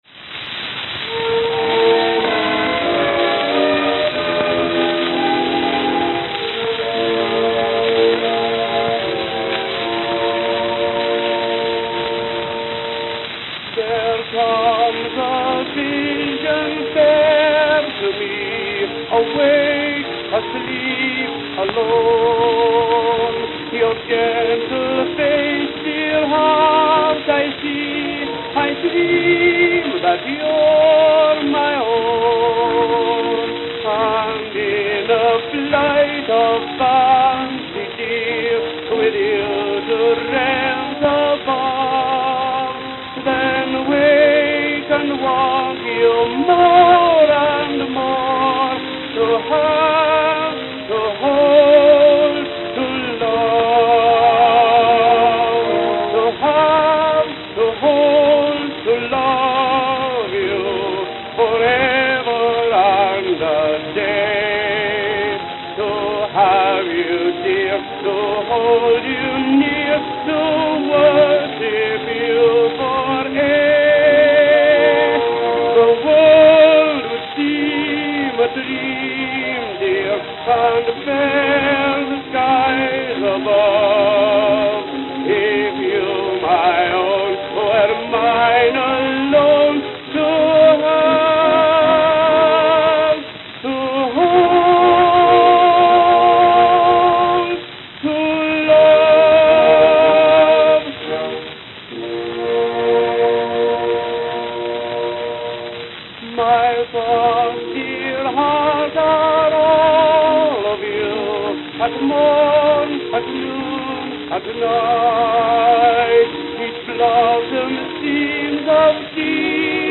Note: Extremely worn.